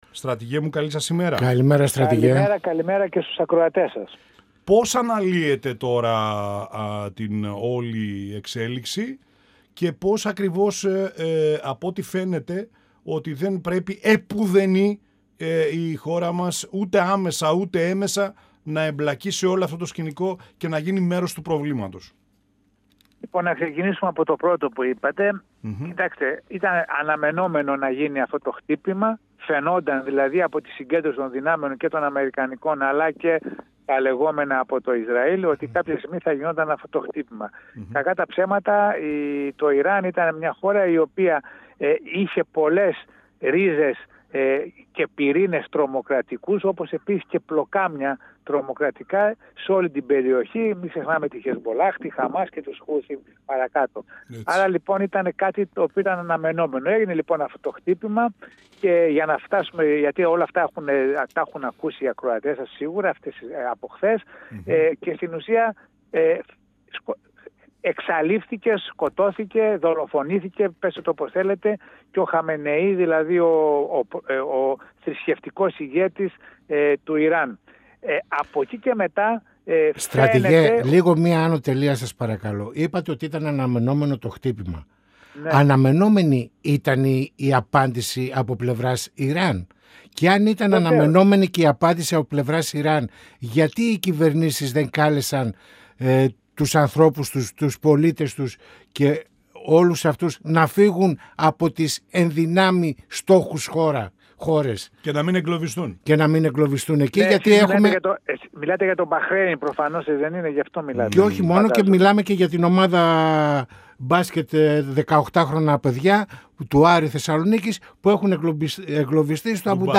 Στις πρώτες μέρες του πολέμου στη Μέση Ανατολή, στους στόχους ΗΠΑ και Ισραήλ σε σχέση με την αλλαγή του θεοκρατικού καθεστώτος στο Ιράν, καθώς και την πολλαπλή αντίδραση του Ιράν με μη αναμενόμενες πυραυλικές επιθέσεις του στις γειτονικές αραβικές χώρες του Κόλπου αναφέρθηκε ο Επίτιμος Αρχηγός του ΓΕΣ Στρατηγός Γιώργος Καμπάς , μιλώντας στην εκπομπή «Πανόραμα Επικαιρότητας» του 102FM της ΕΡΤ3.